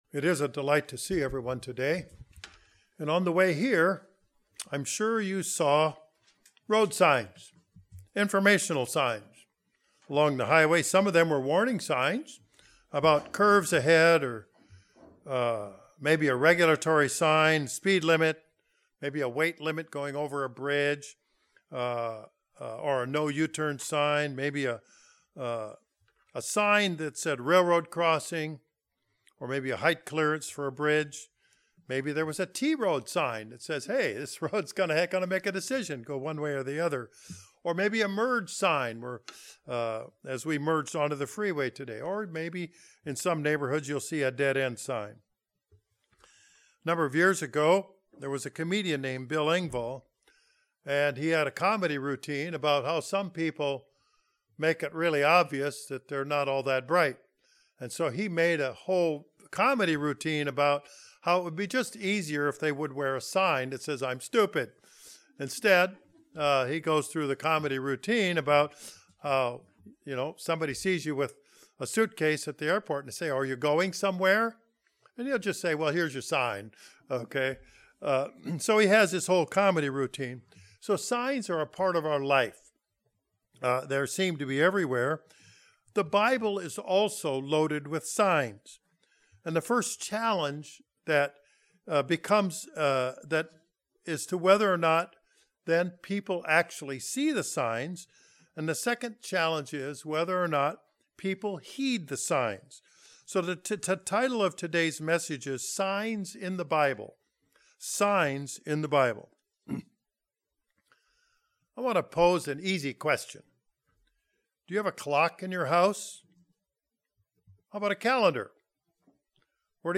Given in Northwest Arkansas